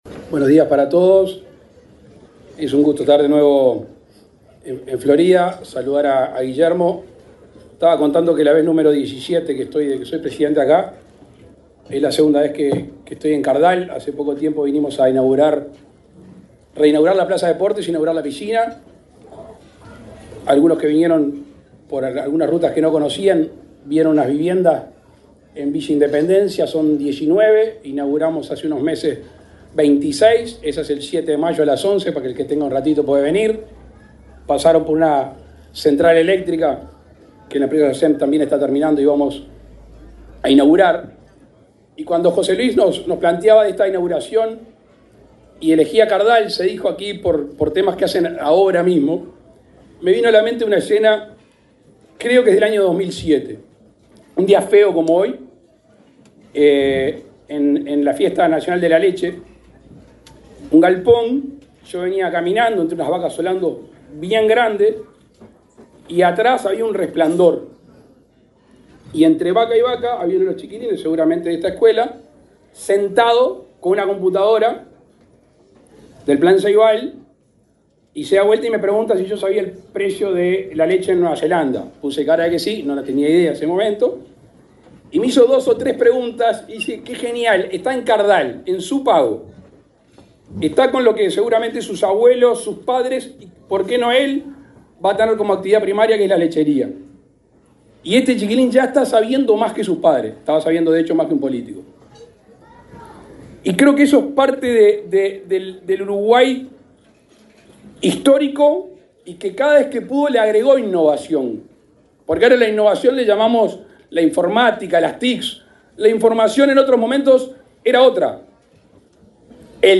Palabras del presidente Luis Lacalle Pou
Palabras del presidente Luis Lacalle Pou 16/04/2024 Compartir Facebook Twitter Copiar enlace WhatsApp LinkedIn El presidente de la República, Luis Lacalle Pou, encabezó, este martes 16 en la localidad de Cardal, en Florida, el acto de implementación del Ferrocarril Central.